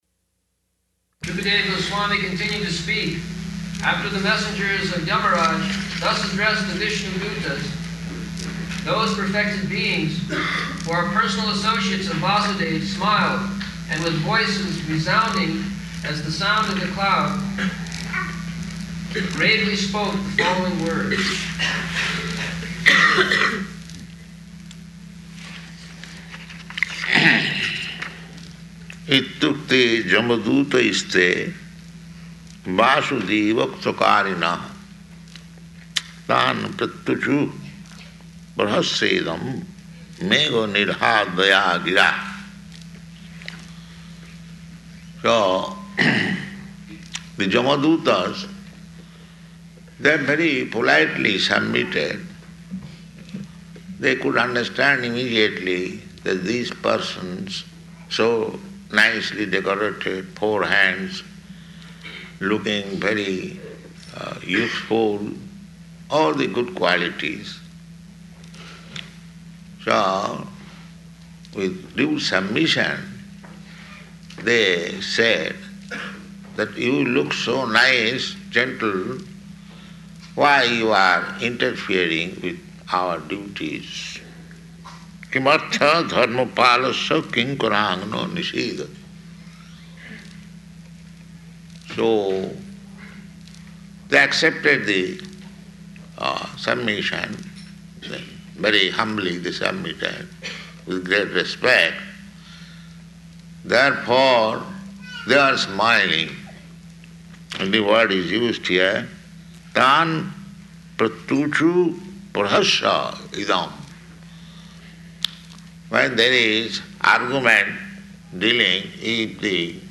Location: San Francisco